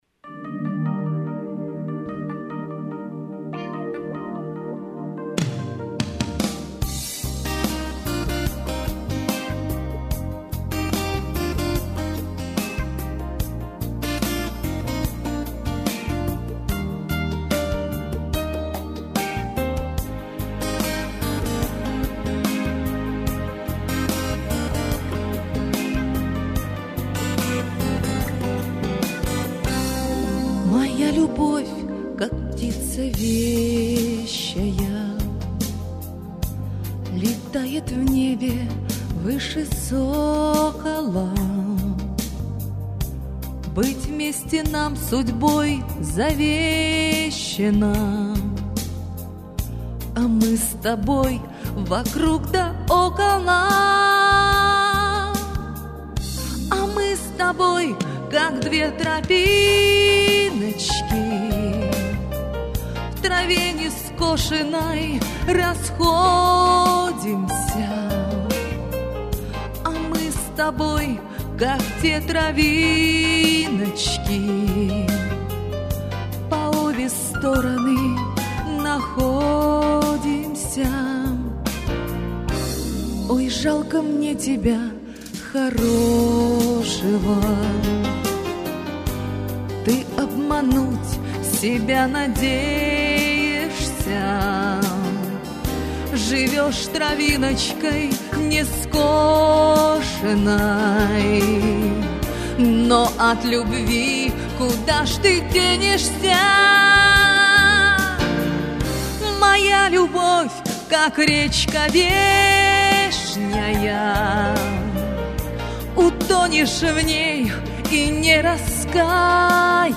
звучит шансоном